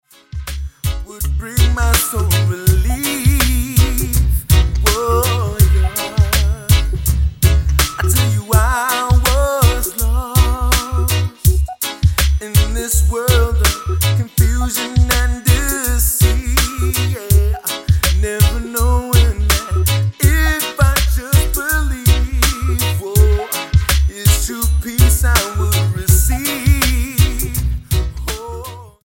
STYLE: Reggae
Tonga gospel reggae rules!